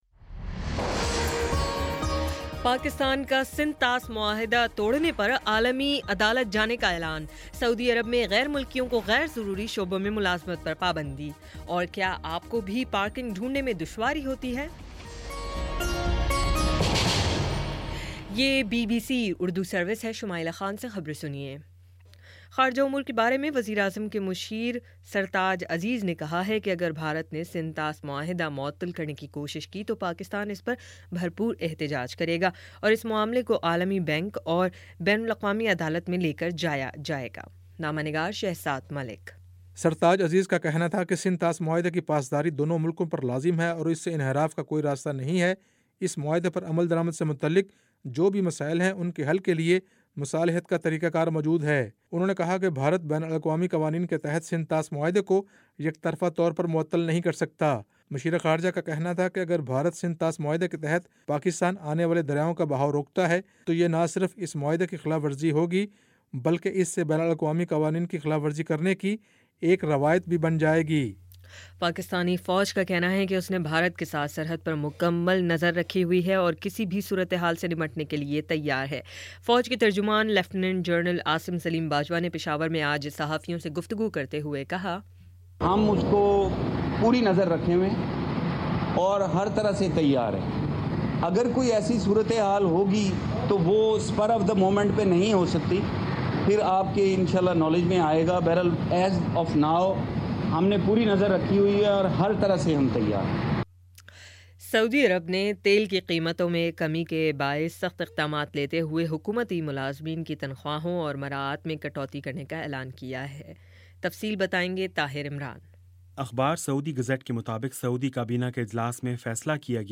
ستمبر 27 : شام پانچ بجے کا نیوز بُلیٹن